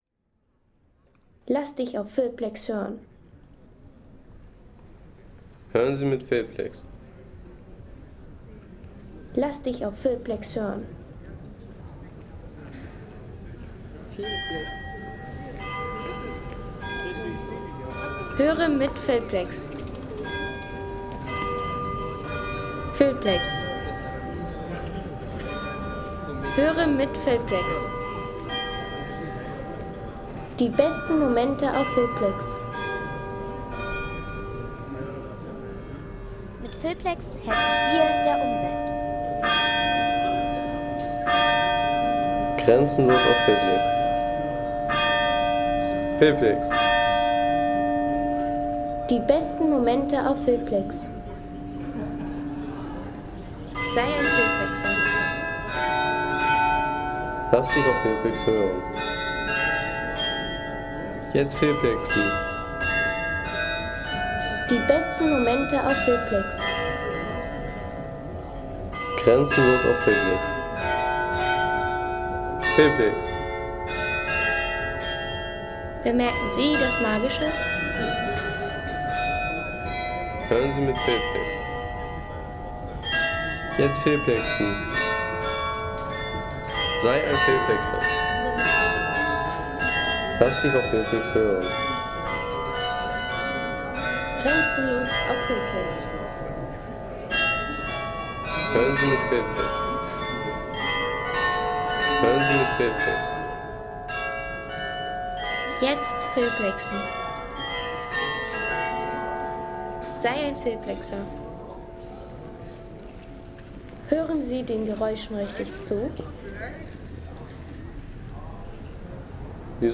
Grazer Glockenspiel Home Sounds Technik Uhren/Uhrwerke Grazer Glockenspiel Seien Sie der Erste, der dieses Produkt bewertet Artikelnummer: 263 Kategorien: Technik - Uhren/Uhrwerke Grazer Glockenspiel Lade Sound.... Grazer Glockenspiel – Ein klingendes Wahrzeichen der Altstadt.